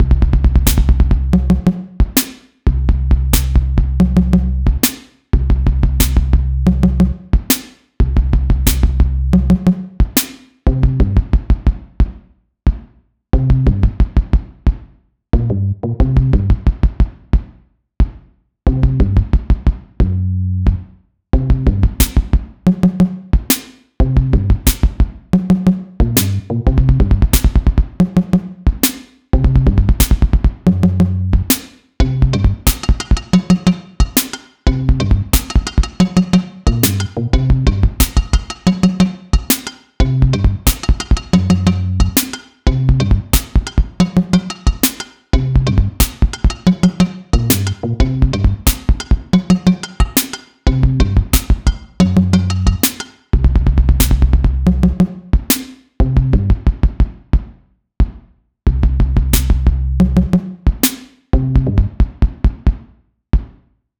Pieza de Electroclash
Música electrónica
melodía
repetitivo
rítmico
sintetizador